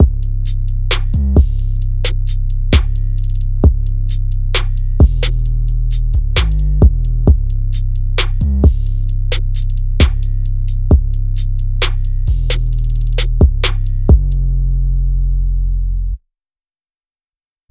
drumloop 11 (132 bpm).wav